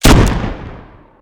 shoot.wav